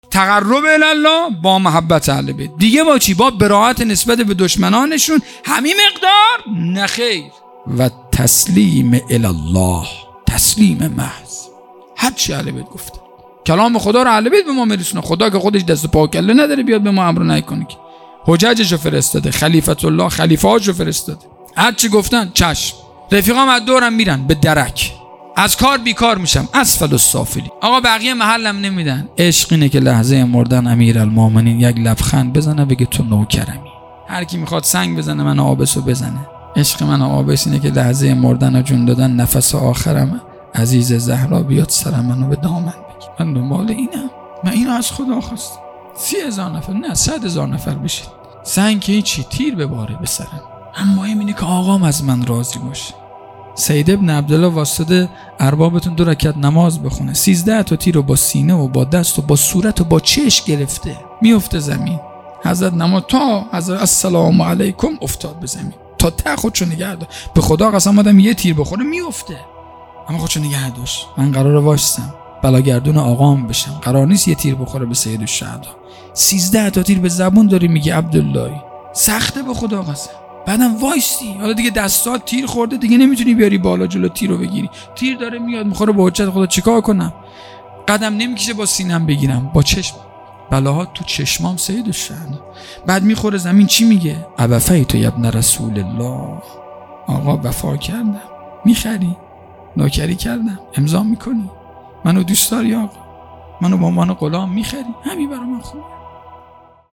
شهادت حضرت معصومه (س) 25 آبان 1401 | محفل دیوانگان حضرت اباالفضل (ع) مشهد